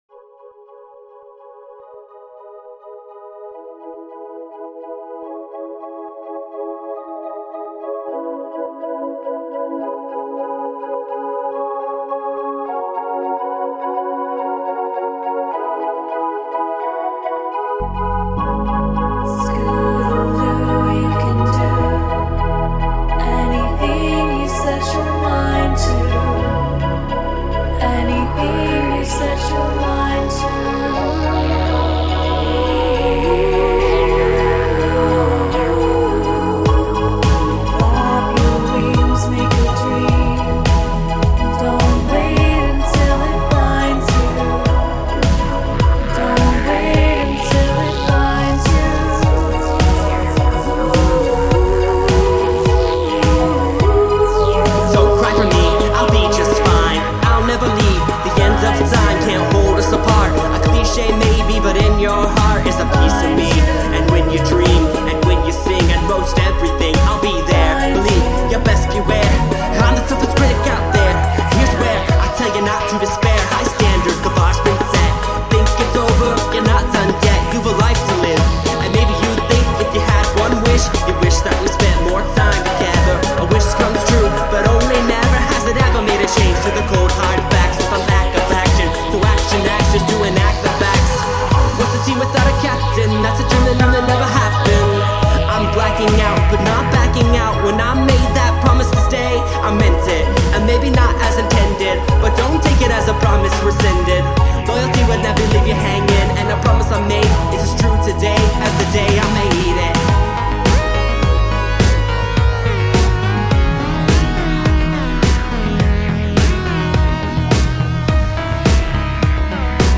It takes a pretty simplistic approach like the original.